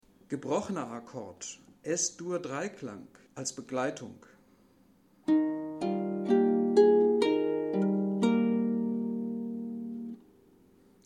Gebrochener Akkord als Begleitung